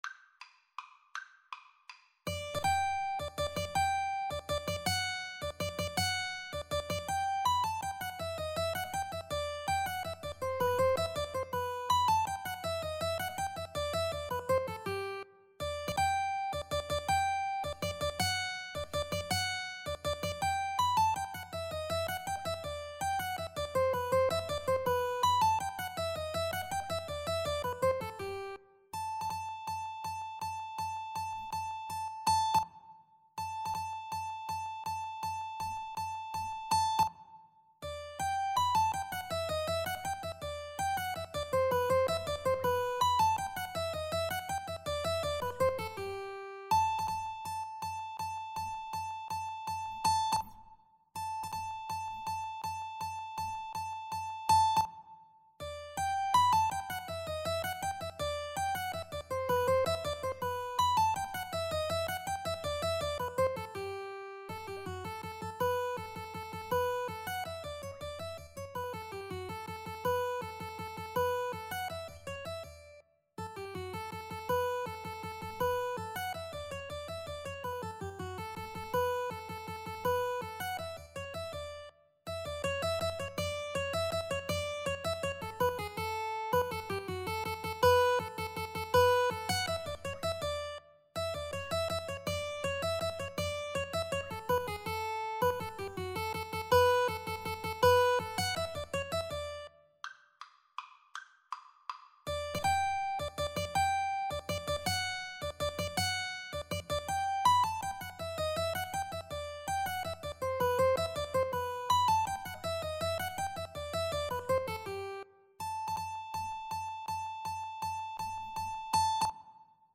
Free Sheet music for Mandolin-Guitar Duet
G major (Sounding Pitch) (View more G major Music for Mandolin-Guitar Duet )
Traditional (View more Traditional Mandolin-Guitar Duet Music)
German